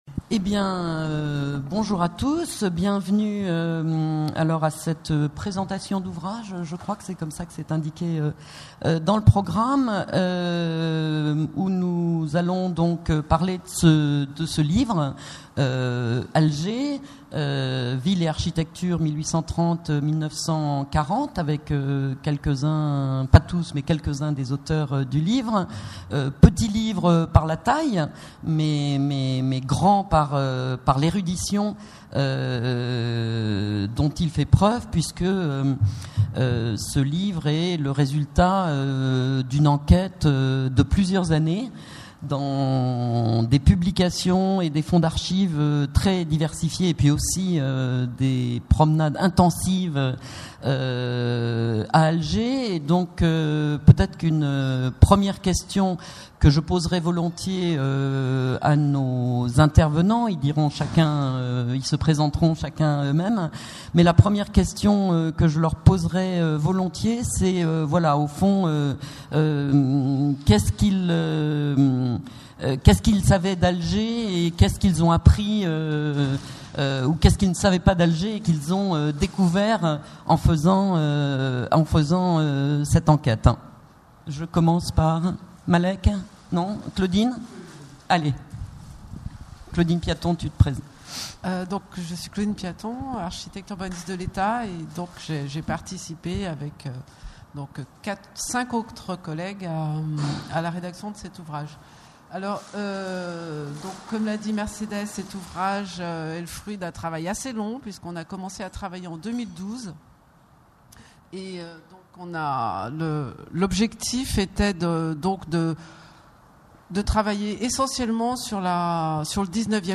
Présentation de l’ouvrage Alger. Ville et Architecture 1830-1940 (éd. Honoré Clair, 2016) par ses auteurs